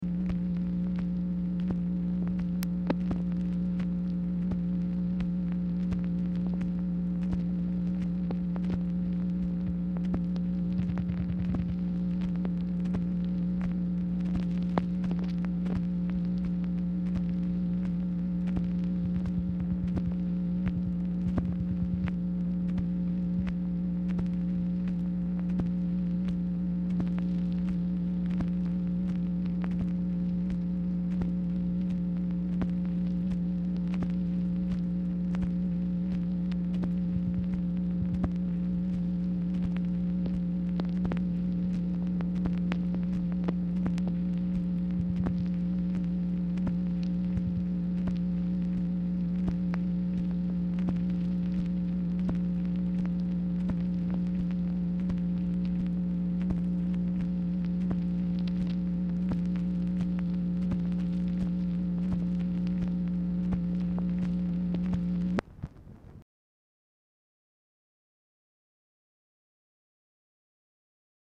Telephone conversation # 9921, sound recording, MACHINE NOISE, 3/30/1966, time unknown | Discover LBJ
Format Dictation belt